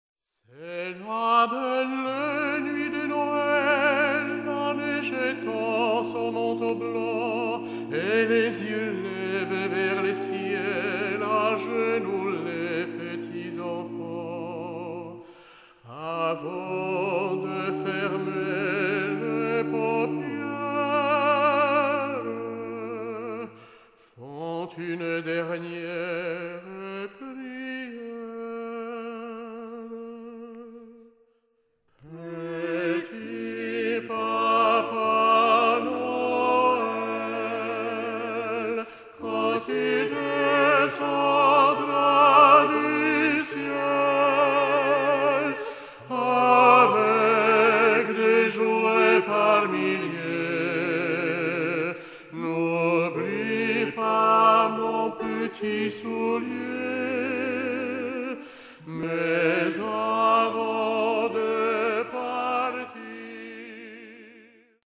Récital A Capella tout public